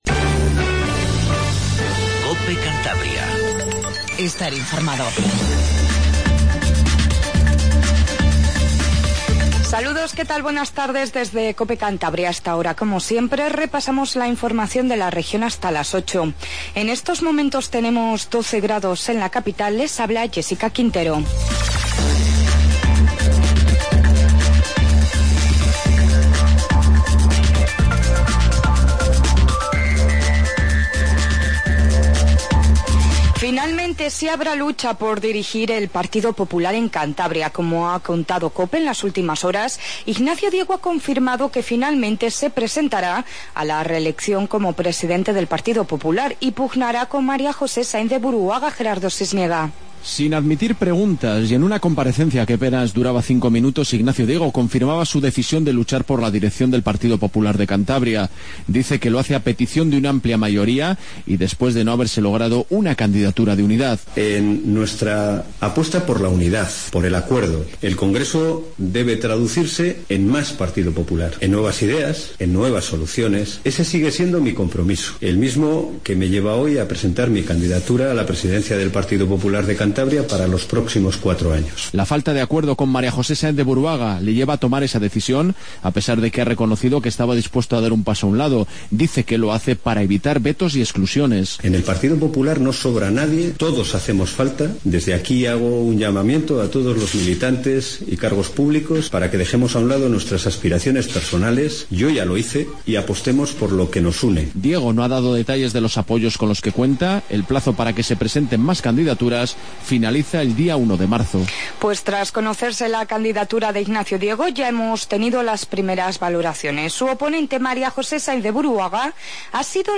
INFORMATIVO DE TARDE 19:50